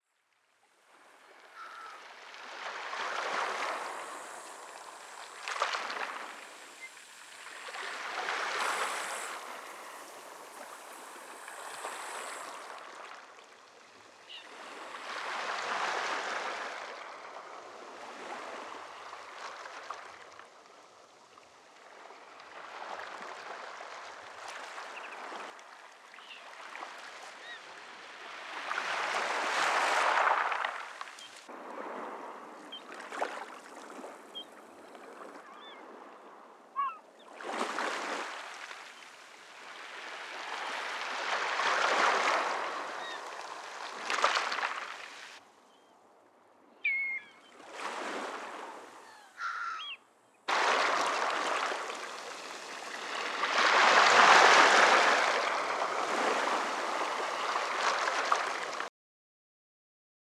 海浪.mp3